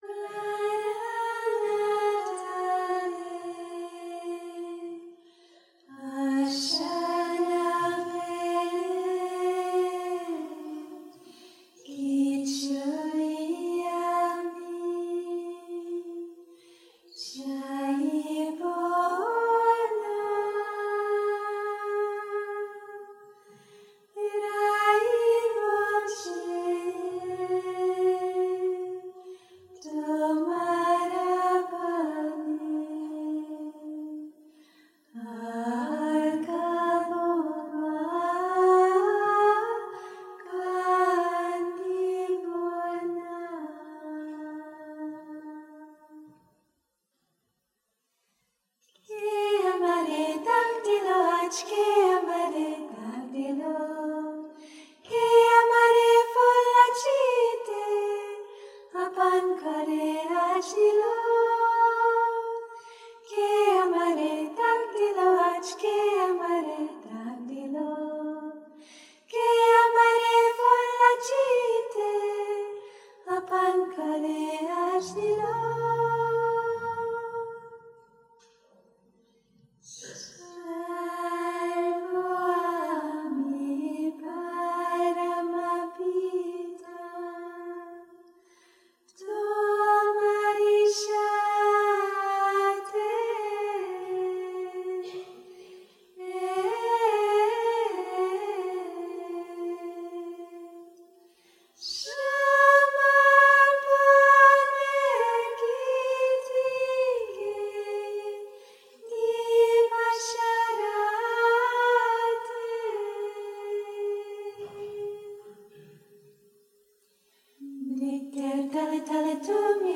mantrische Lieder